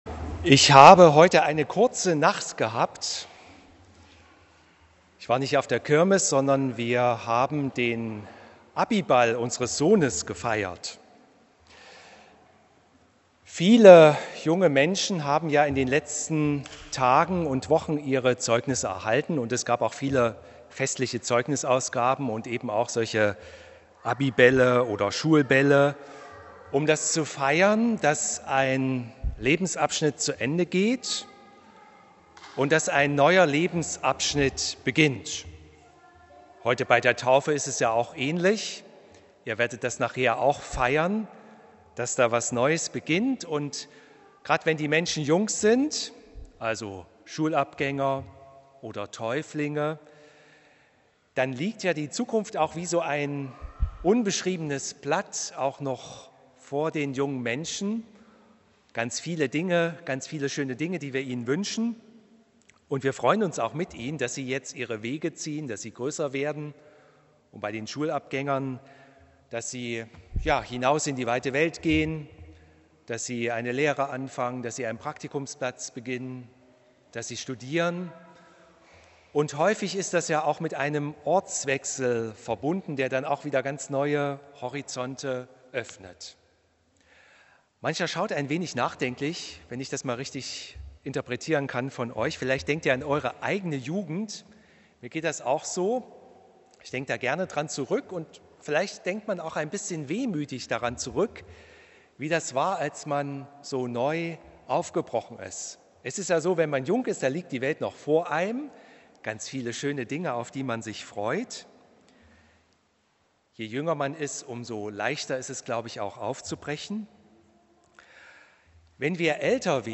Hören Sie hier die Predigt zu 1. Mose 1,12,1-4